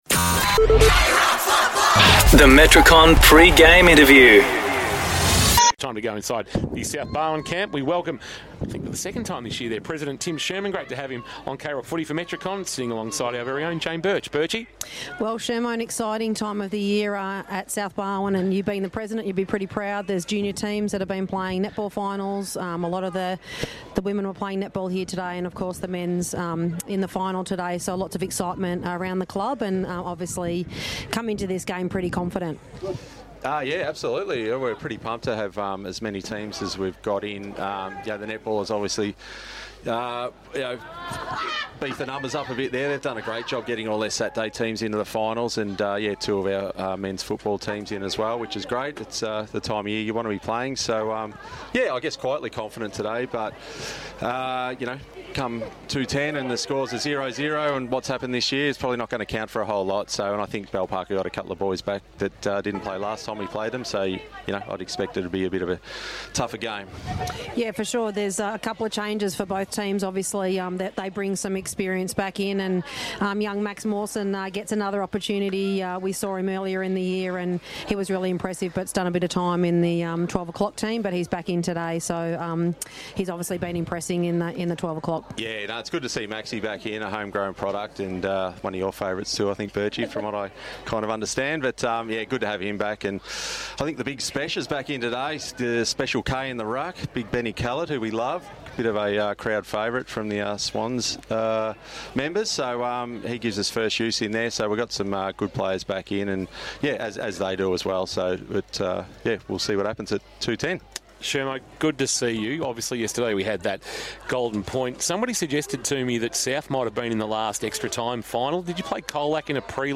2024 - GFNL - Elimination Final - South Barwon vs. Bell Park: Pre-game interview